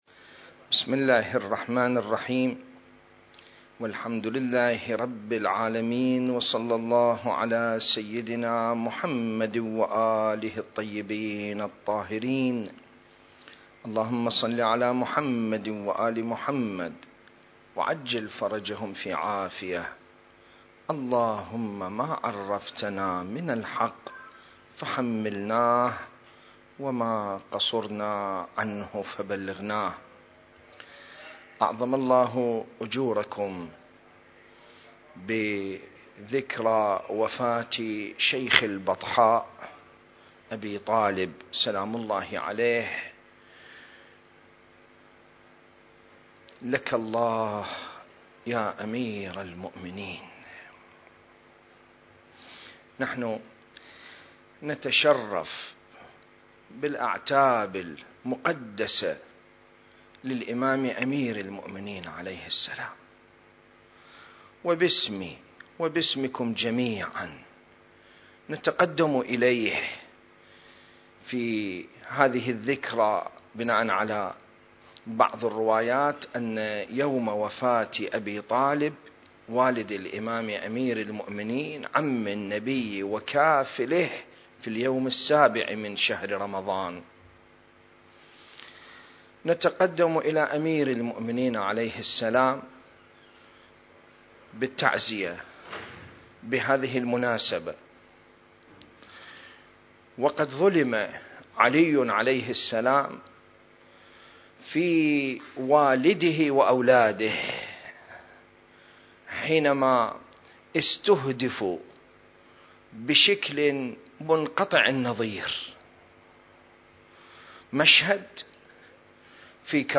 سلسلة: الإمام المهدي (عجّل الله فرجه) في دعاء الافتتاح (2) المكان: العتبة العلوية المقدسة التاريخ: 2021